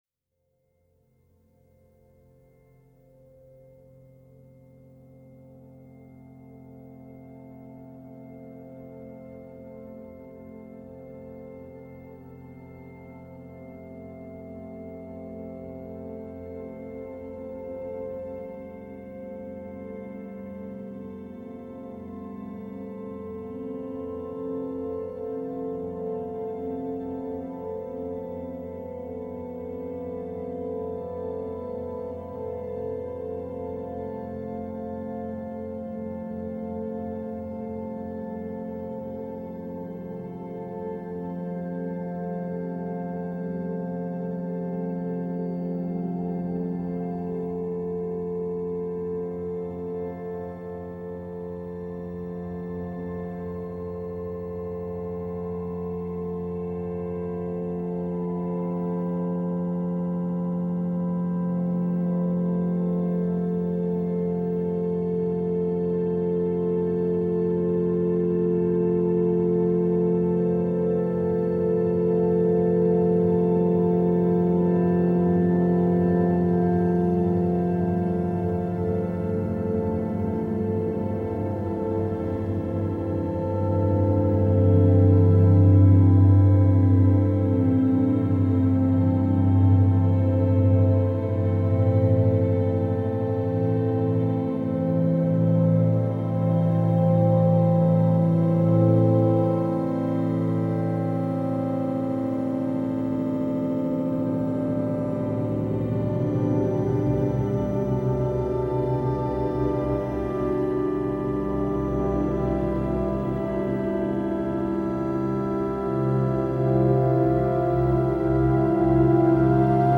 like its slow and almost still pace and its hypnotic nature.